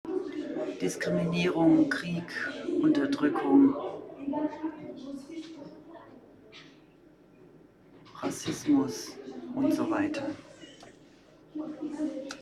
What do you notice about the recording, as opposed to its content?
MS Wissenschaft @ Diverse Häfen Standort war das Wechselnde Häfen in Deutschland. Der Anlass war MS Wissenschaft